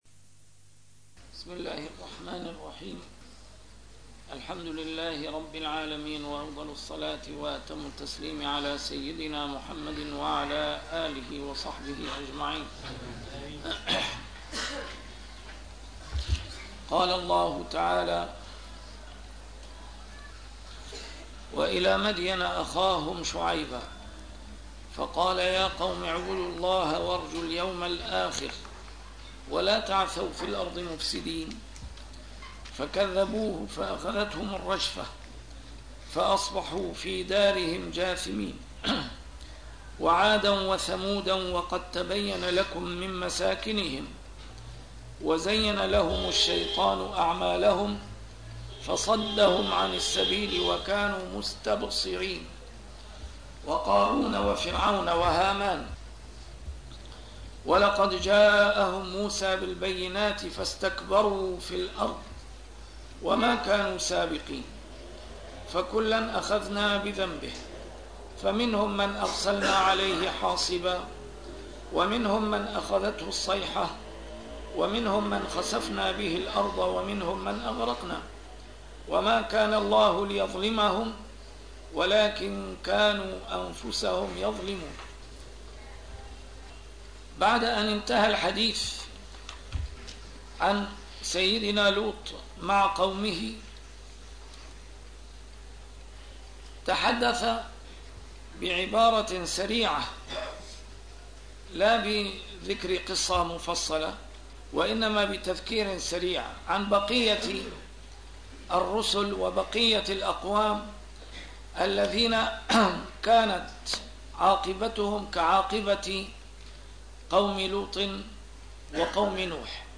A MARTYR SCHOLAR: IMAM MUHAMMAD SAEED RAMADAN AL-BOUTI - الدروس العلمية - تفسير القرآن الكريم - تسجيل قديم - الدرس 302: العنكبوت 36-40